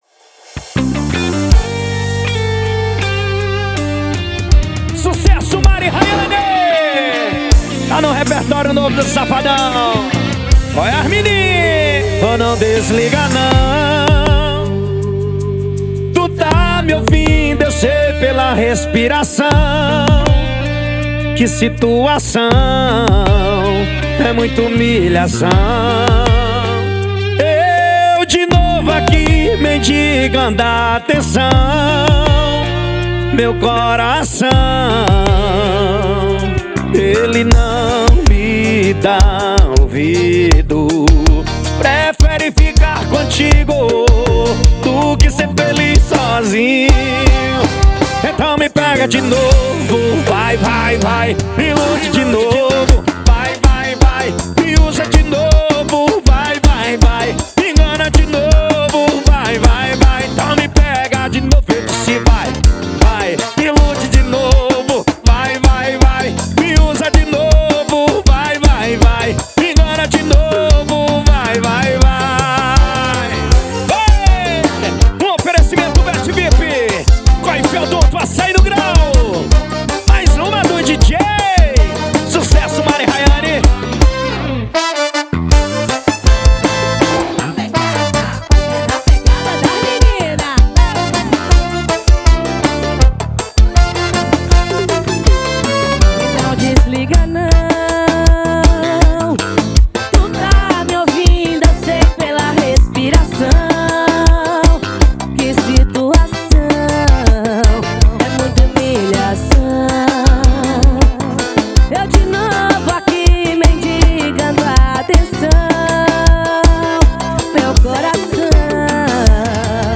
2024-02-14 22:37:08 Gênero: Forró Views